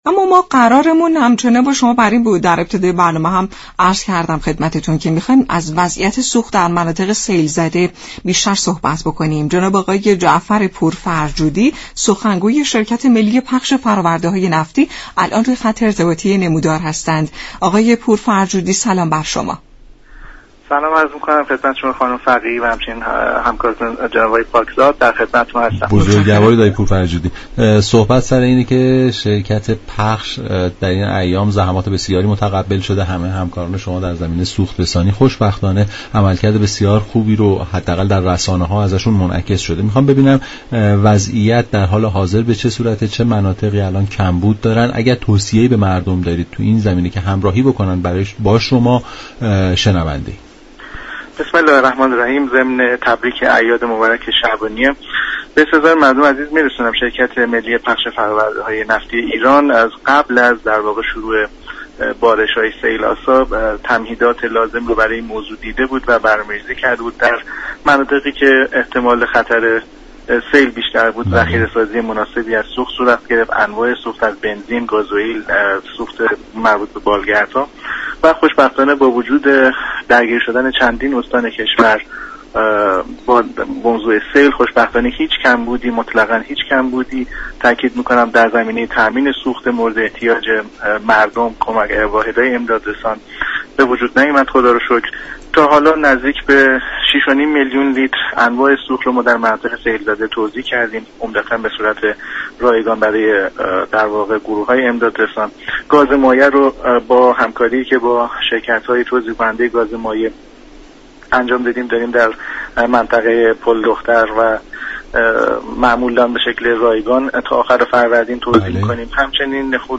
این گفت و گو را در ادامه باهم می شنویم.